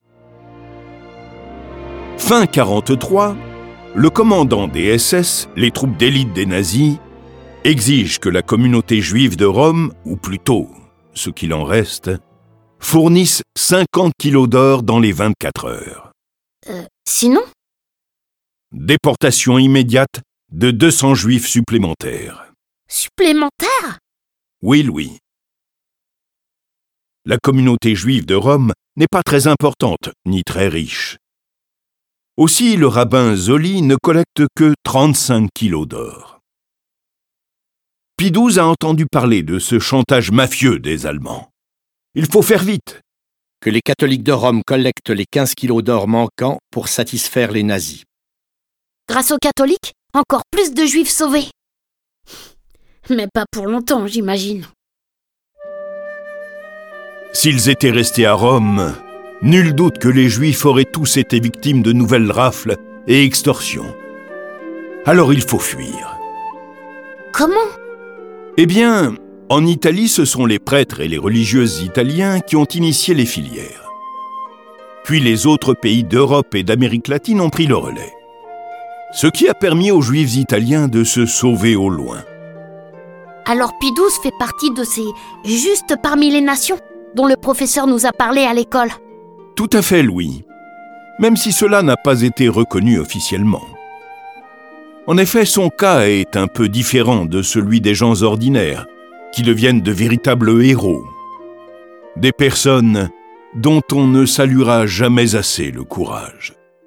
Diffusion distribution ebook et livre audio - Catalogue livres numériques
Le récit et les dialogues sont illustrés avec les musiques de Bach, Beethoven, Borodine, Charpentier, Chopin, Corelli, Debussy, Delibes, Dvorak, Faure, Grieg, Haendel, Locatelli, Massenet, Mozart, Pergolese, Puccini, Rimski-Korsakov, Satie, Schumann, Tchaikovski, Verdi et Vivaldi.